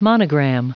Prononciation du mot monogram en anglais (fichier audio)
Prononciation du mot : monogram